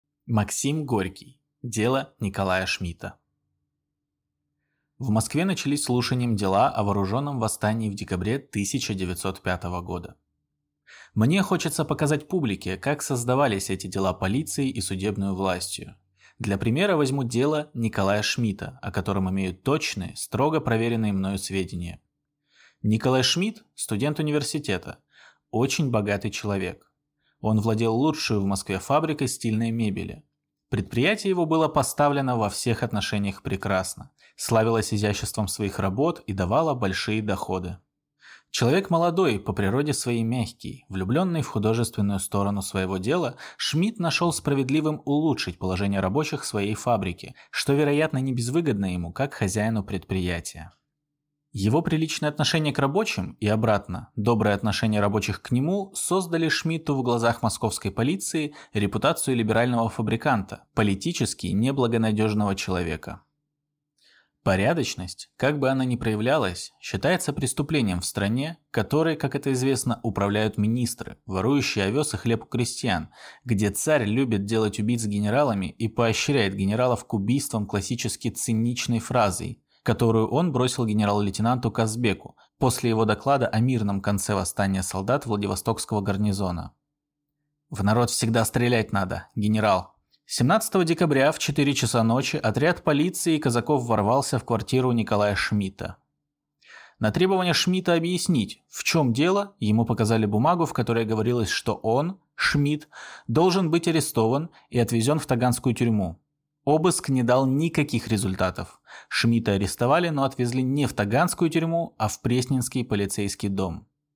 Аудиокнига Дело Николая Шмита | Библиотека аудиокниг